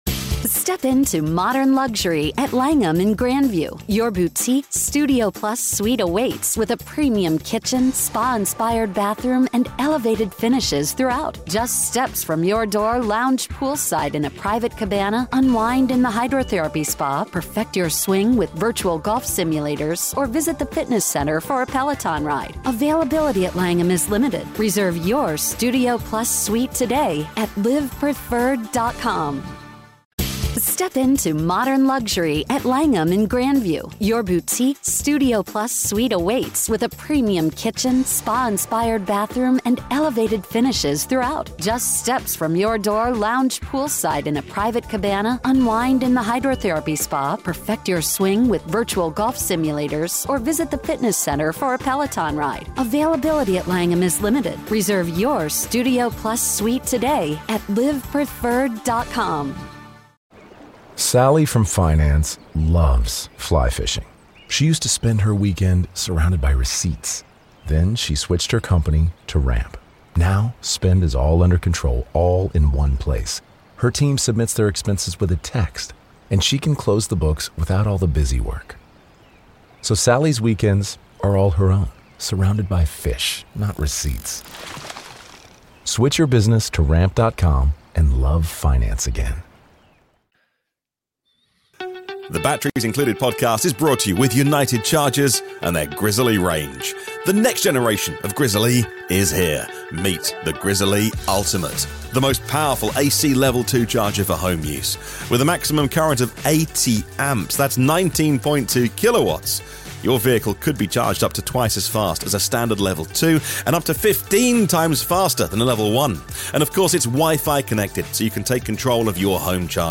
Tune in at 9:30 AM Eastern to catch the show live and participate.